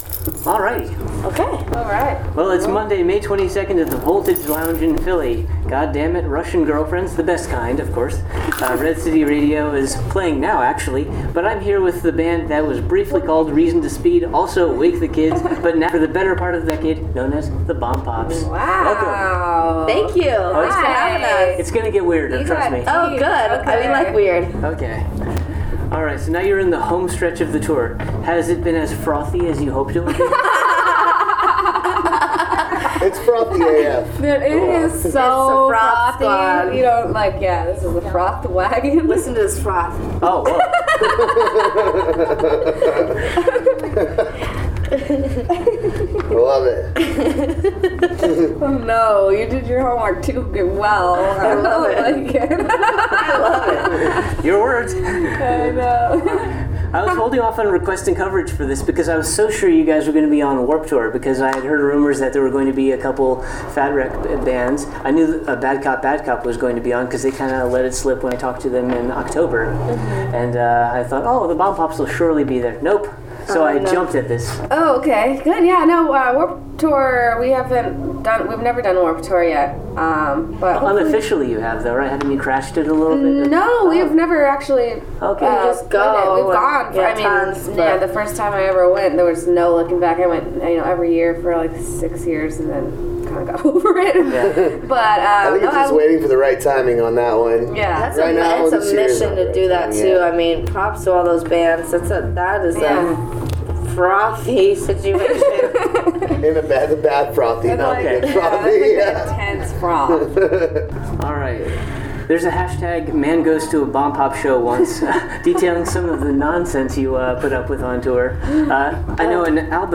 Interview: Bombpops at the Voltage Lounge
78-interview-the-bombpops.mp3